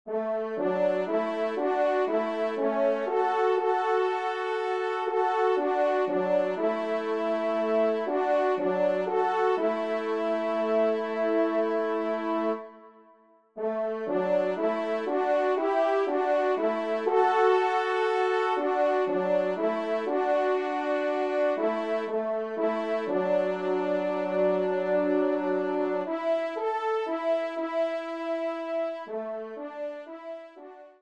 Ensemble (Duo)